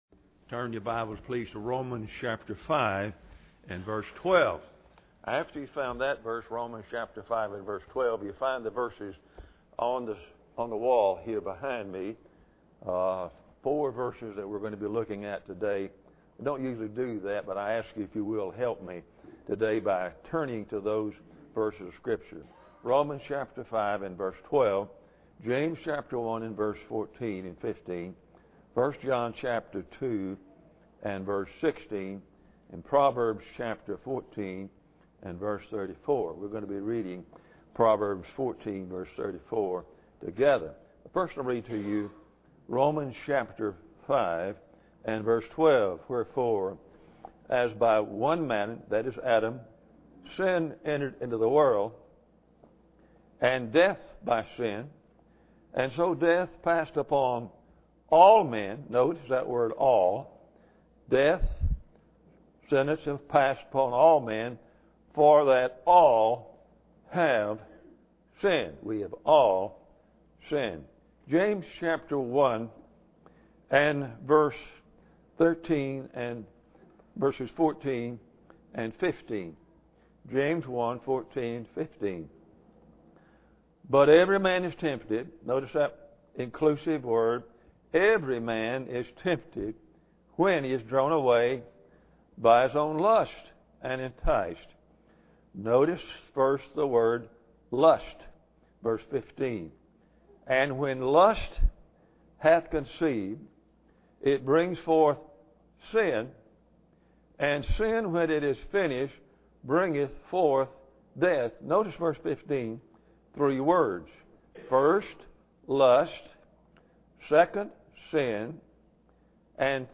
Romans 5:12 Service Type: Sunday Morning Bible Text